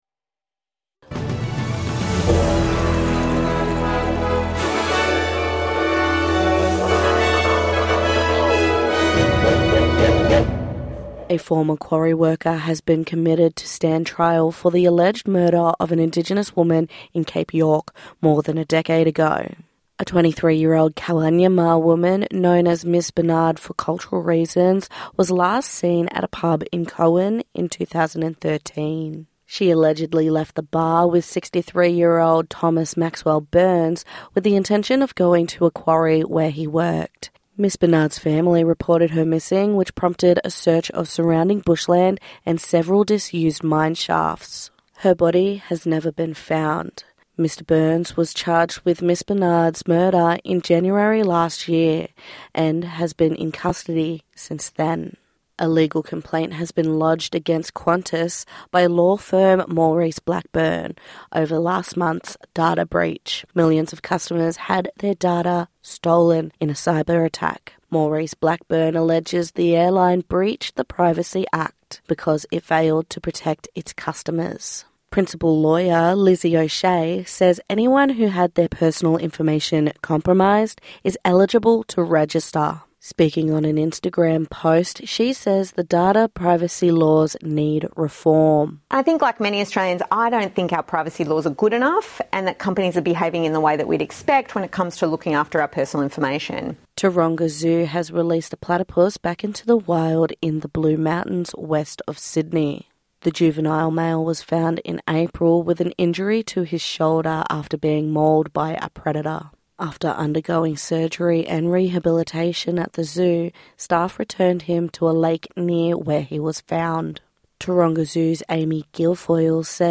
NITV Radio NEWS 18/07/2025